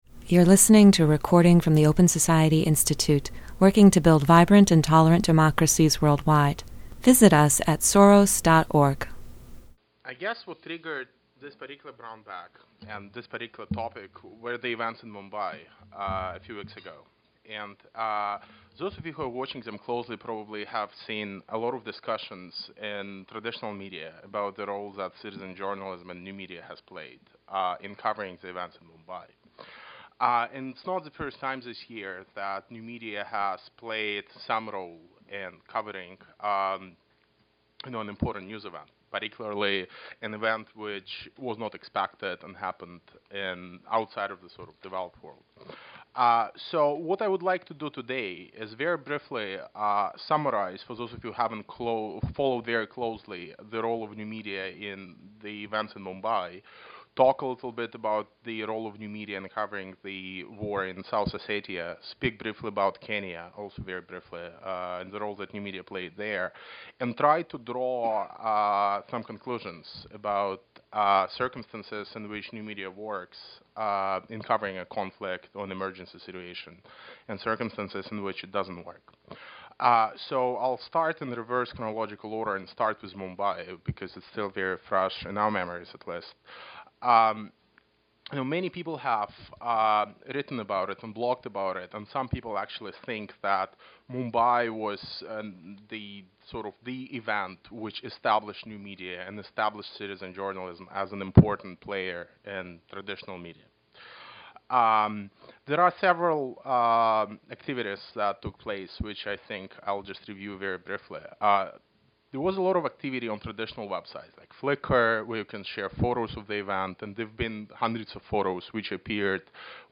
The Open Society Fellowship program presented a discussion with fellow Evgeny Morozov on citizen journalism in recent crises in South Ossetia, Mumbai, and Kenya.